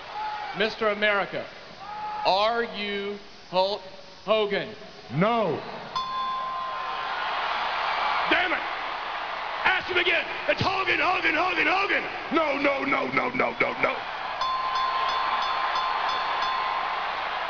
Arm Wrestling Contests!
ding!‘ and ‘bzzt!‘ sound effects!